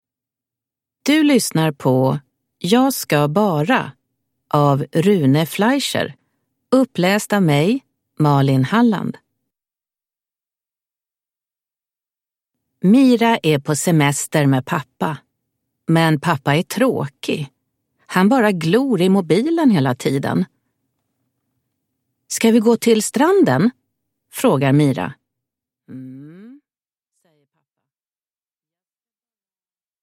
Jag ska bara ... – Ljudbok